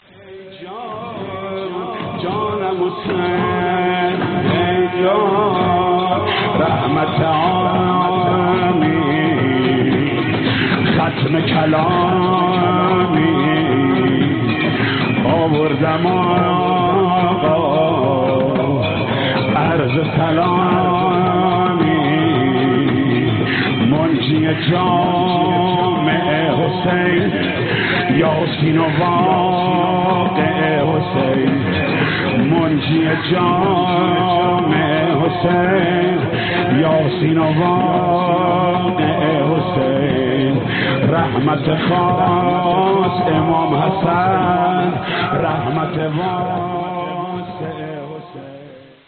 نوحه
دیگر پیشواز مذهبی ایرانسل